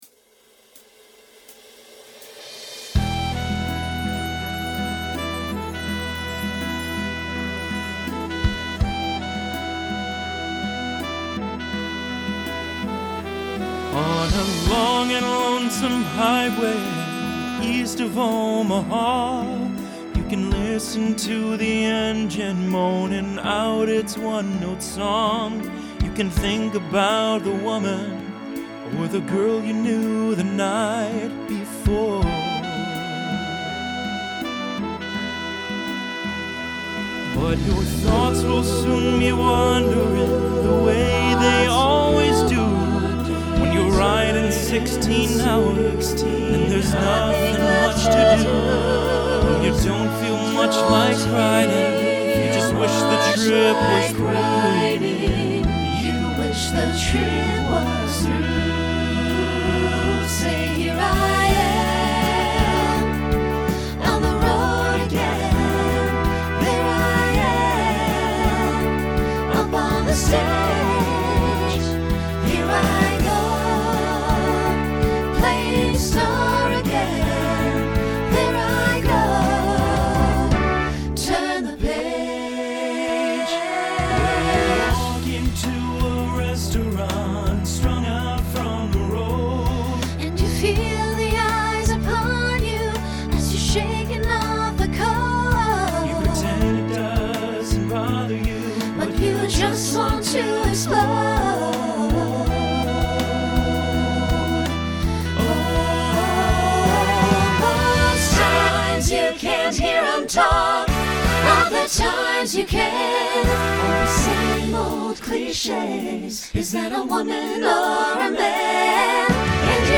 Decade 1970s Genre Rock Instrumental combo
Voicing SATB